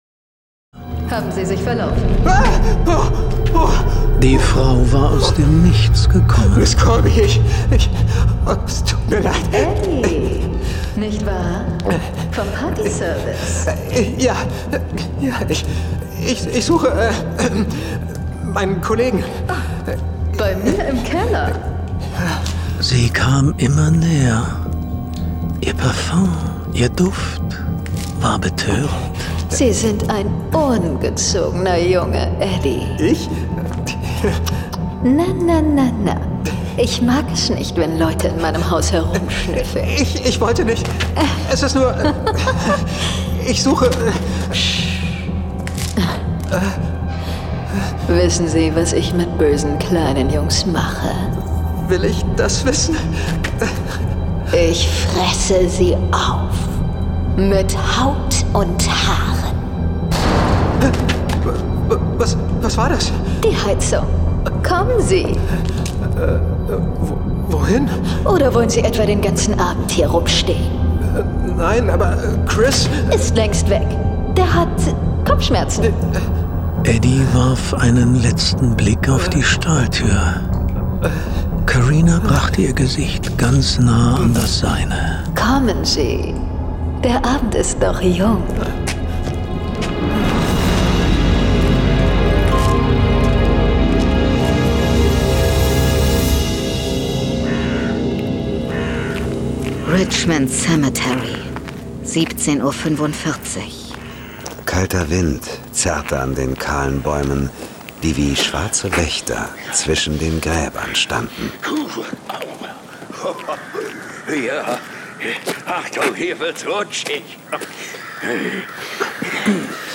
John Sinclair - Folge 186 Carinas Todesparties. Hörspiel. Jason Dark (Autor) Dietmar Wunder , diverse (Sprecher) Audio-CD 2025 | 1.